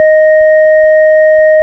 Index of /90_sSampleCDs/Keyboards of The 60's and 70's - CD1/ORG_FarfisaCombo/ORG_FarfisaCombo
ORG_VIP Spy  D#5.wav